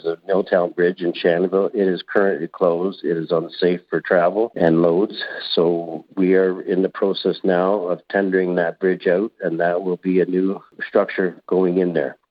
Quinte News talked with the mayor of Tyendinaga Township Claire Kennelly about the year ahead.